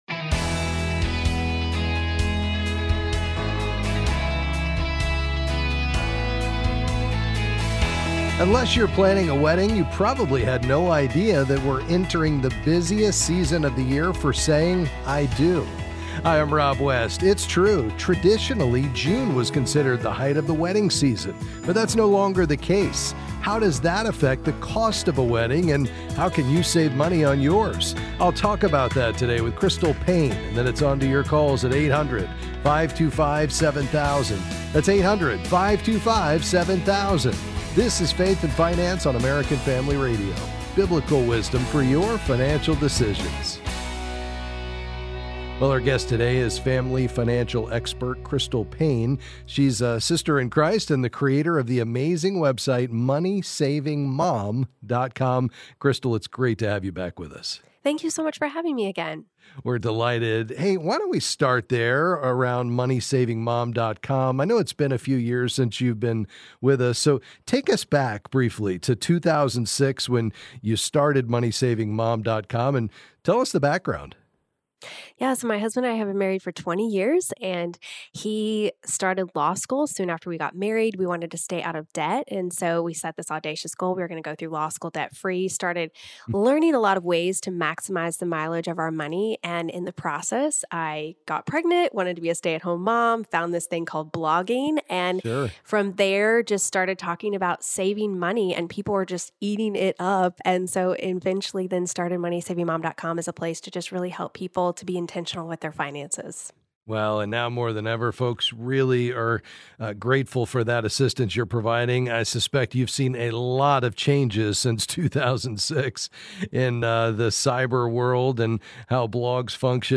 Then he’ll answer your calls on various financial topics.